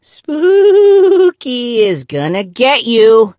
gus_ulti_vo_05.ogg